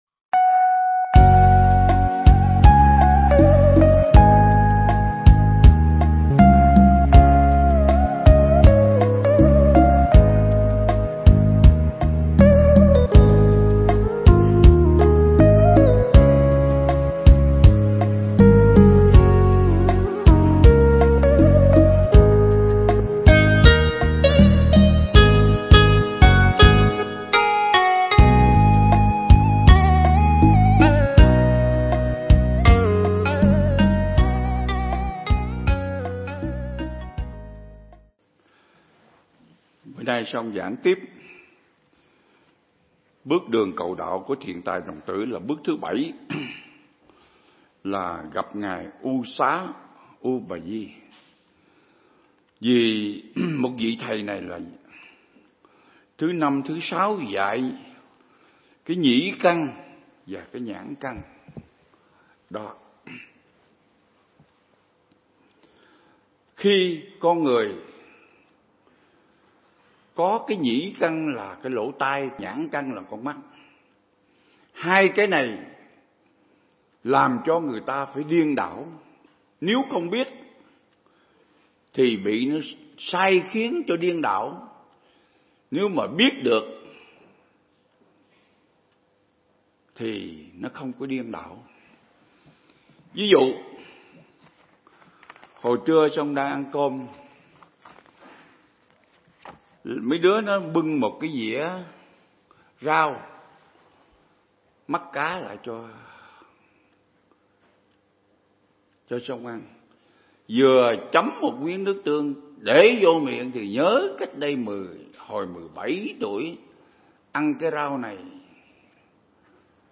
Mp3 Pháp Thoại Ứng Dụng Triết Lý Hoa Nghiêm Phần 36